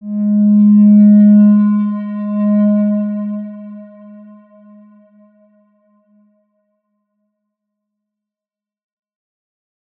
X_Windwistle-G#2-pp.wav